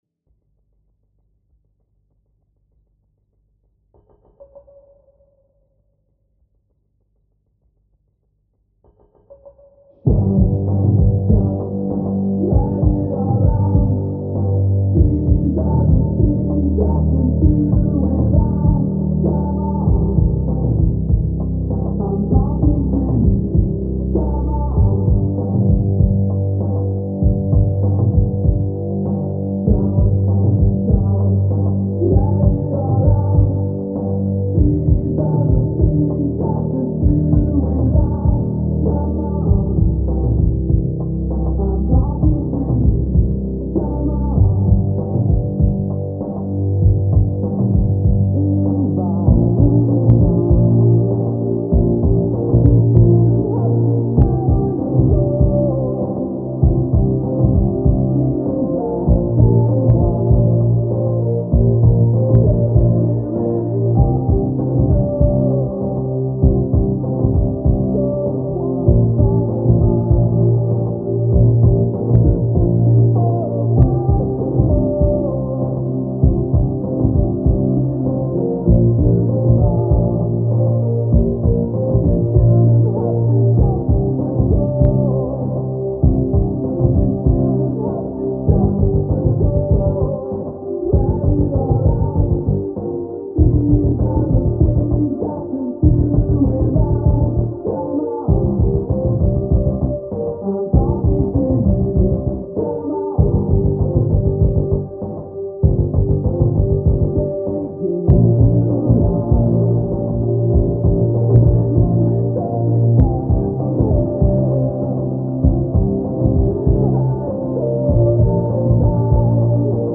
playing from another room.